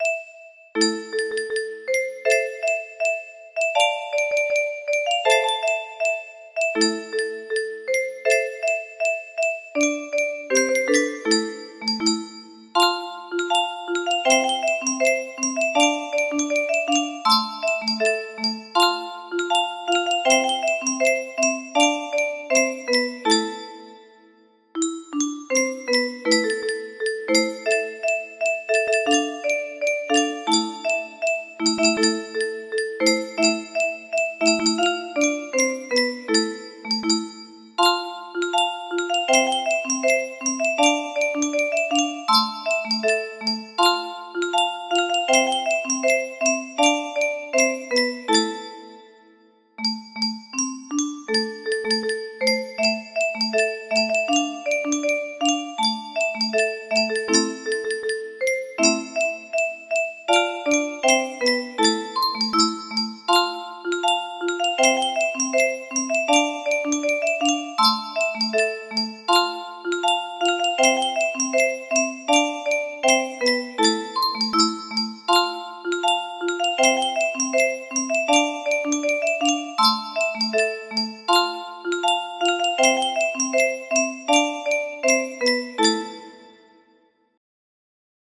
Wellerman - Sea Shanty music box melody